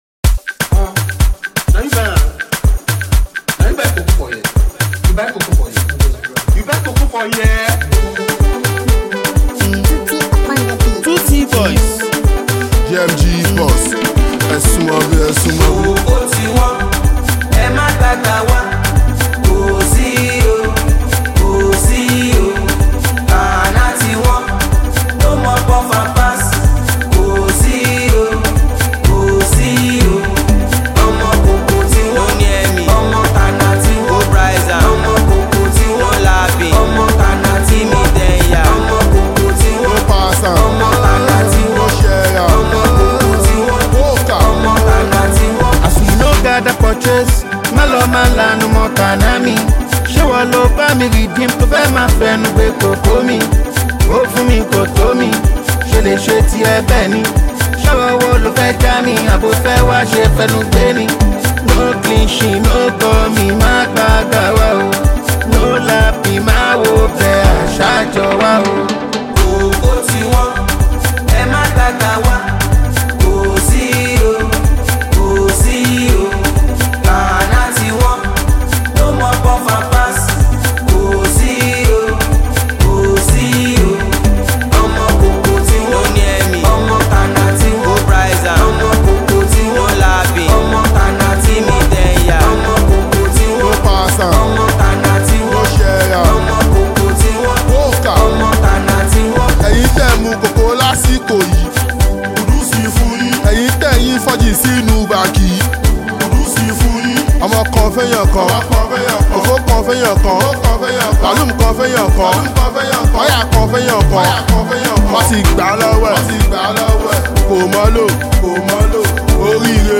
Afrobeats
street-hop
gritty, energetic flow